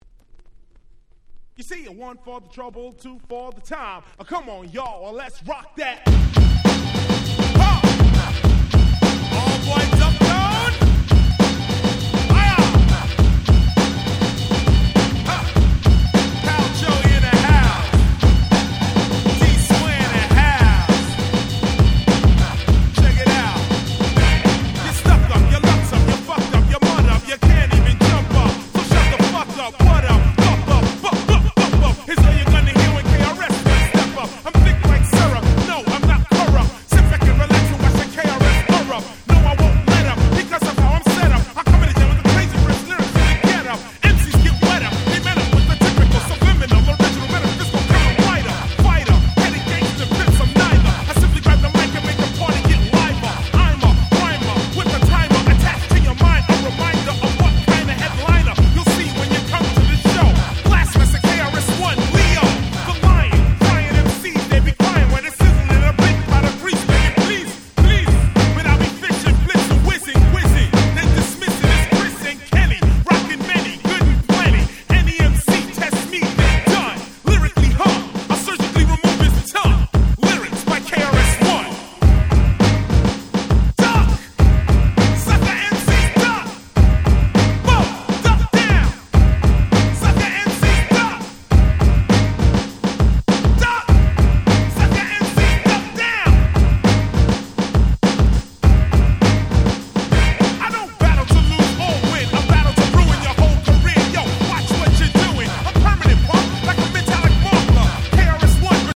92' Hip Hop Super Classics !!